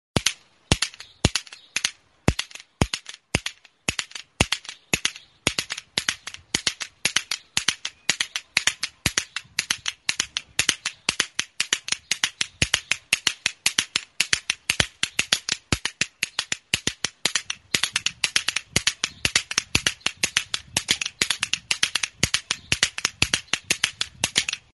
Idiophones -> Struck -> Indirectly
KALAKA. Oiartzun, 02/26/2021.
Recorded with this music instrument.
Zurezko palatxo bat da. Larru zati batekin lotuta, bi aldeetan kirtenik gabeko beste bi palatxo ditu.